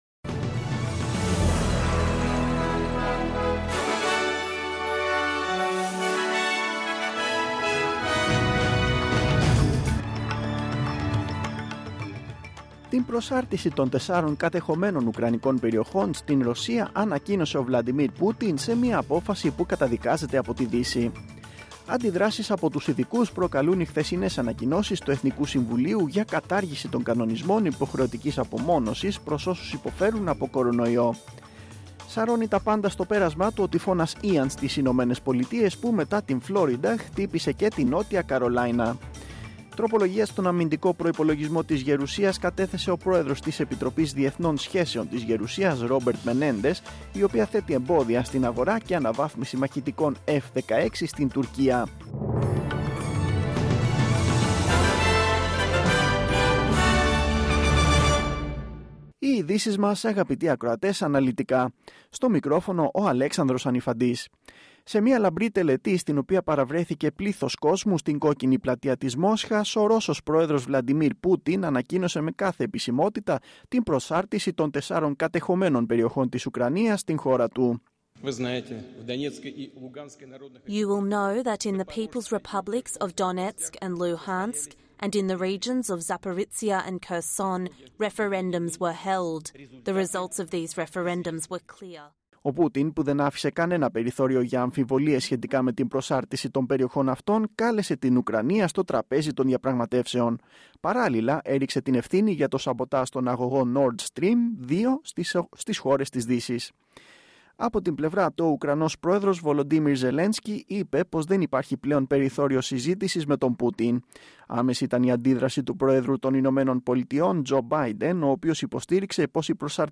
Το αναλυτικό δελτίο ειδήσεων του Ελληνικού Προγράμματος της ραδιοφωνίας SBS, στις 4 μμ.
News in Greek.